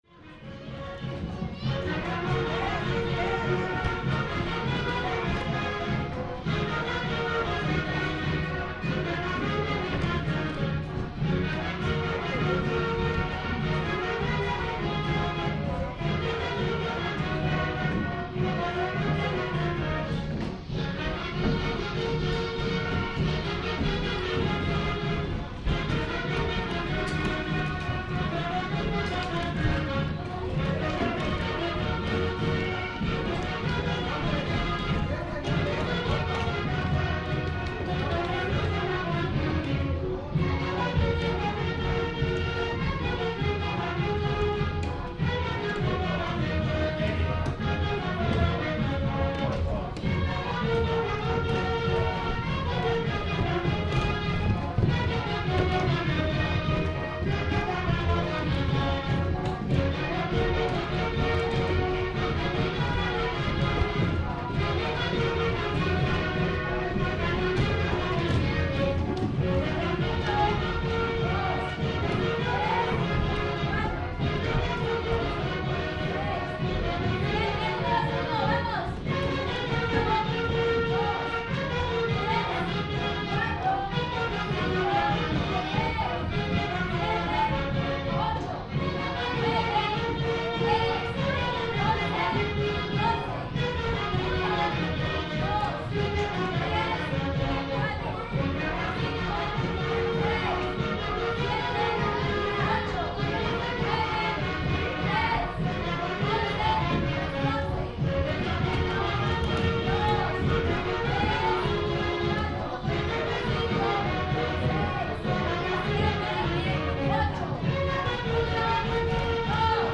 Bailable
Este es un ensayo de la danza
Datos: Nambahashandó significa en lengua chiapaneca "Baile Zapateado".
Lugar de la grabación: Chiapa de Corzo, Chiapas; Mexico.
Equipo: Minidisc NetMD MD-N510, micrófono de construcción casera (más info)